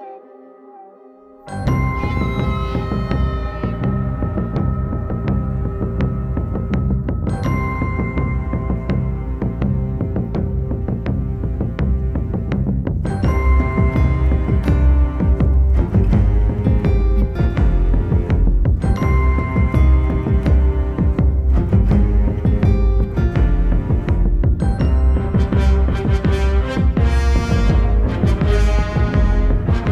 Genre: Soundtrack